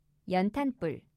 연탄 [연:-뿔]